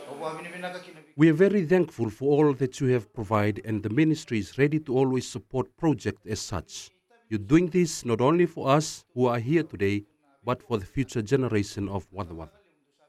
While speaking at the ground-breaking ceremony, Minister for Forestry Kalaveti Ravu expressed his gratitude towards the villagers for their contribution towards this initiative.